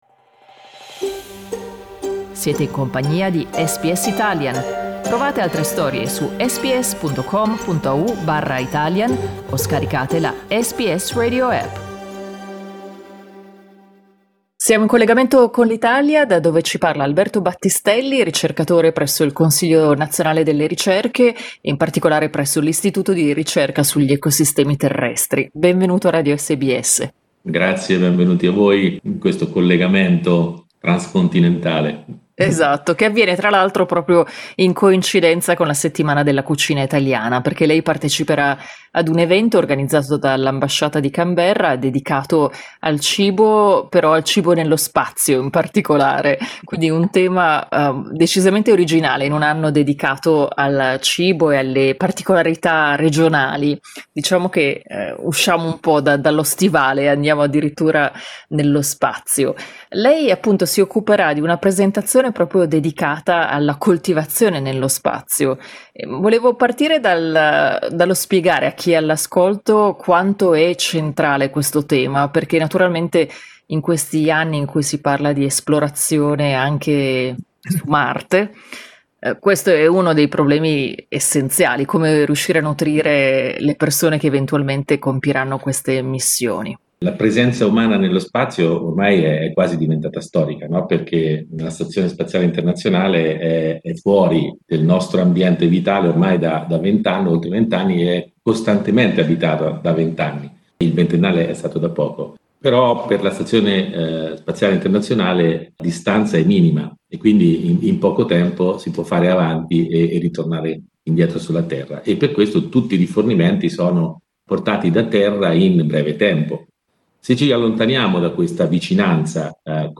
La ricerca scientifica si sta concentrando sulla possibilità di produrre cibo nello spazio, garantendo così ai viaggiatori delle future missioni verso Marte risorse che li renderebbero autosufficienti. Tra le sfide della coltivazione di piante nello spazio, spiega ai microfoni di SBS Italian, ci sono la microgravità, che rende più difficile la gestione dell'acqua per inaffiare le piante, le radiazioni, ma anche la sostenibilità e l'affidabilità dei processi.